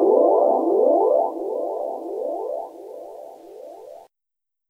Arp (Burgundy).wav